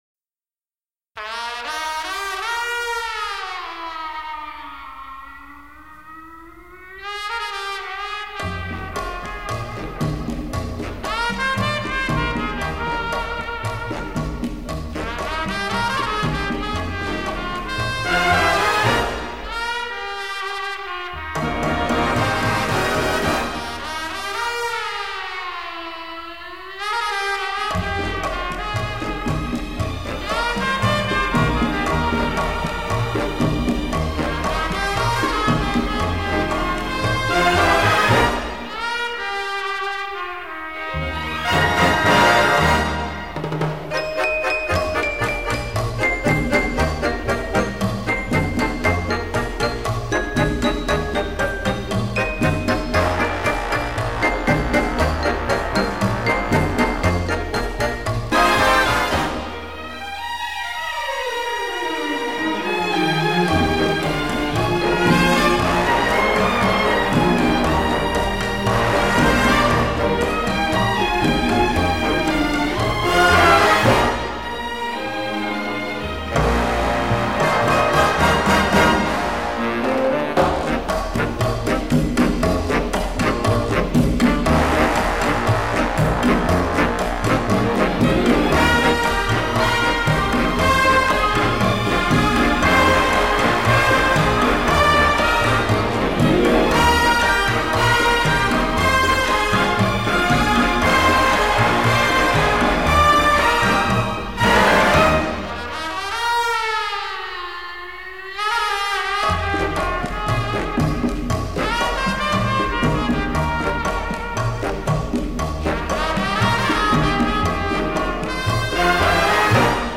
Cha-Cha